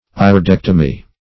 Iridectomy \Ir`i*dec"to*my\, n. [Gr.